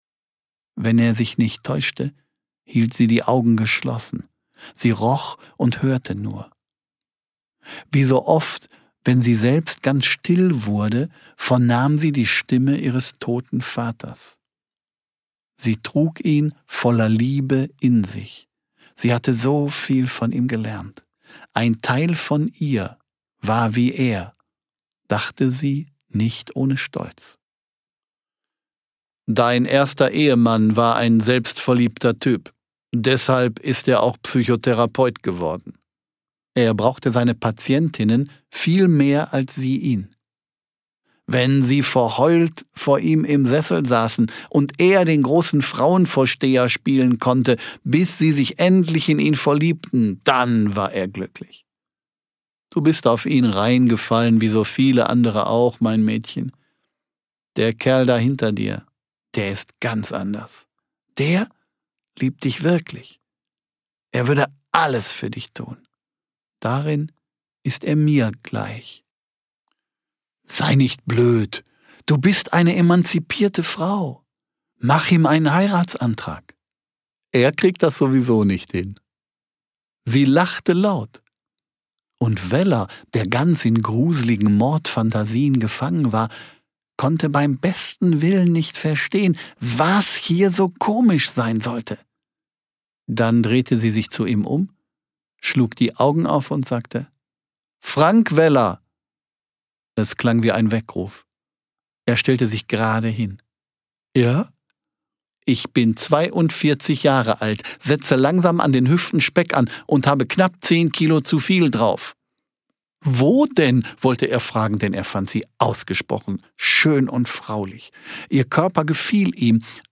Klaus-Peter Wolf (Sprecher)
Schlagworte Ermittlungen • Hörbuch; Krimis/Thriller-Lesung • Krimi • Kriminalromane & Mystery: Polizeiarbeit • Kriminalromane & Mystery: weibliche Ermittler • Kriminalromane & Mystery: Polizeiarbeit • Kriminalromane & Mystery: weibliche Ermittler • Ostfriesenkrimi • Ostfriesische Inseln; Krimis/Thriller • Ostfriesland • Schallplatte • Vinyl